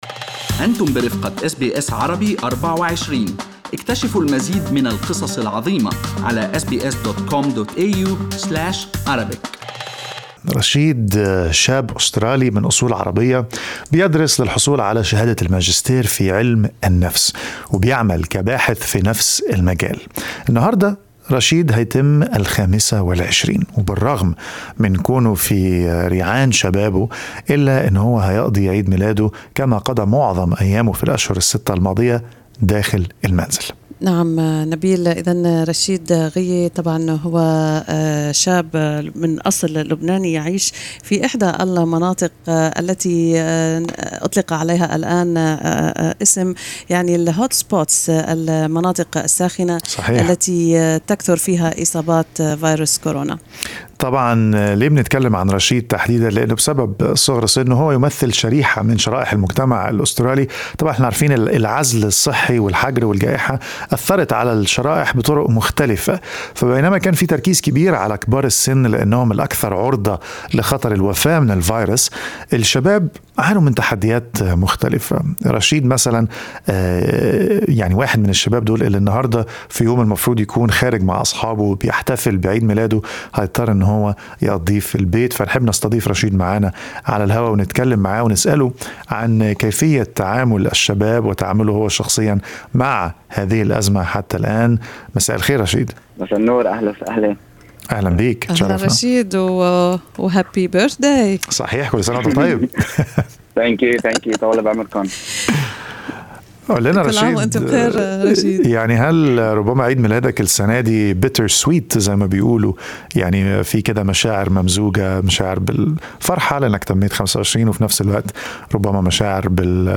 لقاءٌ مع شابٍ أسترالي من أصلٍ لبناني يتحدث عن كيفية تأقلم الشباب مع الواقع الجديد.